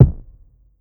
Weird Kick.wav